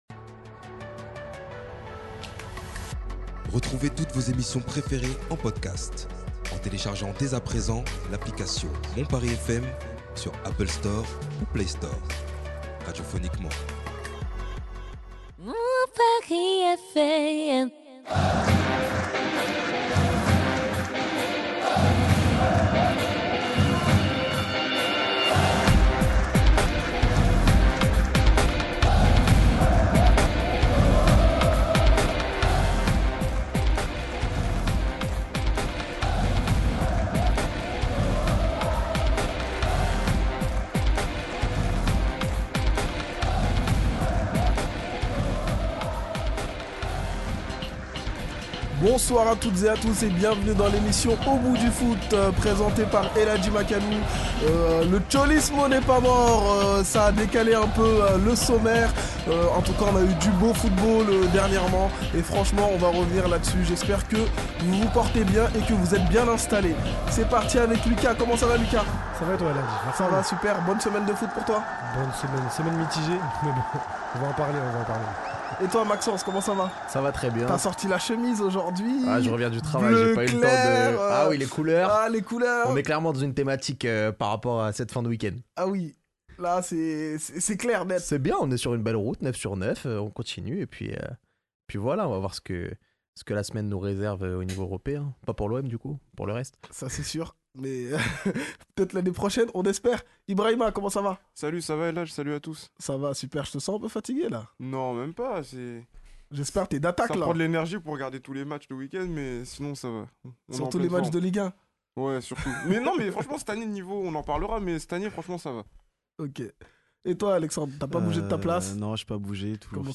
L'interview de Mbappé !